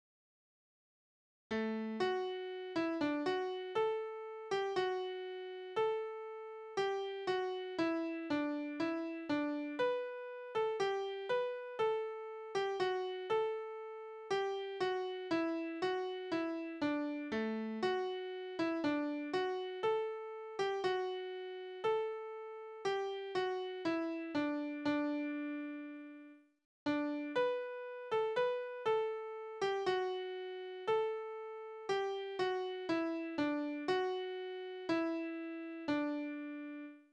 Tonart: D-Dur
Tonumfang: große None
Besetzung: vokal